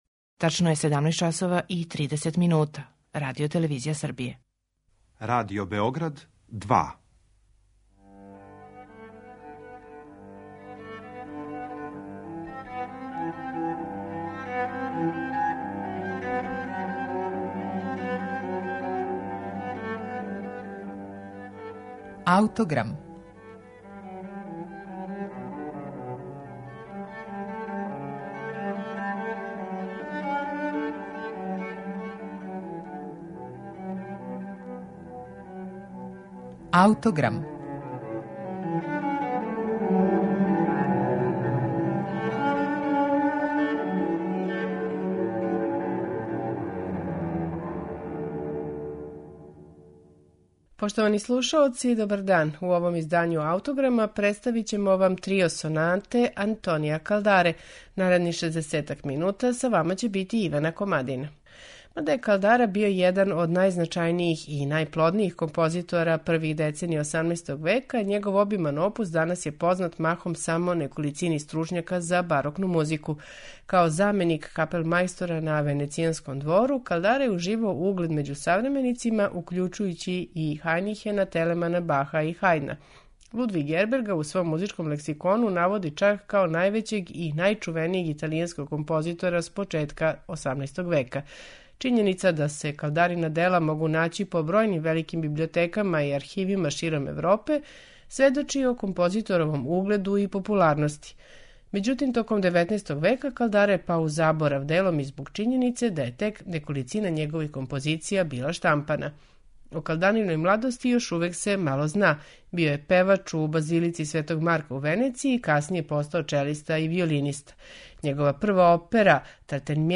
Антонио Калдара: Трио сонате
Генијално владање контрапунктском техником комбиновано је са изразитим личним изражајним стилом.
У вечерашњем Аутограму Трио сонате опус 1 Антонија Калдаре слушаћете у интерпретацији чланова ансамбла „Parnassi Musici".